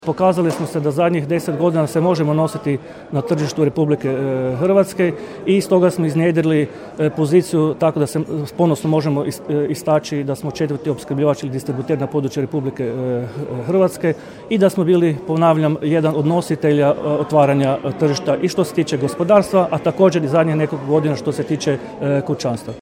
Međimuje plin izborna skupština, Čakovec 17.11.2021.